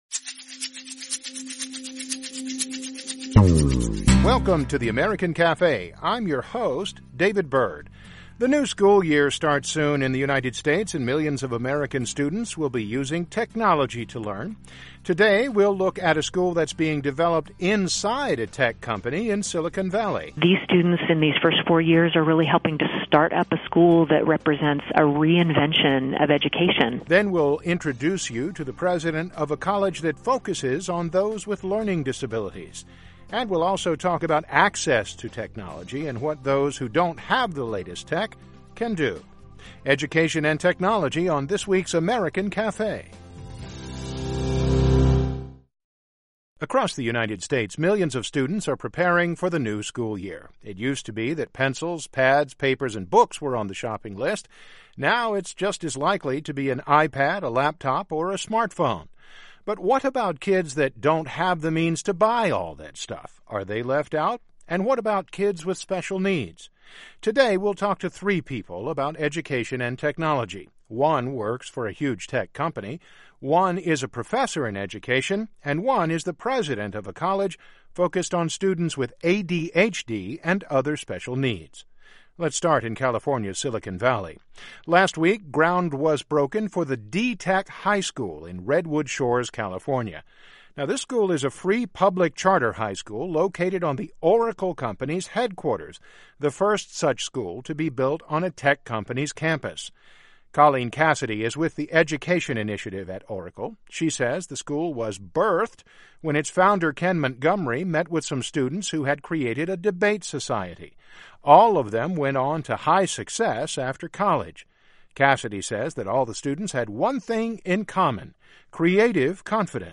What role does technology play in how kids learn? This week, we talk with three educators - one affiliated with a new school on a tech company campus, one who leads a school for students with special needs and one at a liberal arts school in the northeastern U.S. Technology and Education on this week's American Cafe.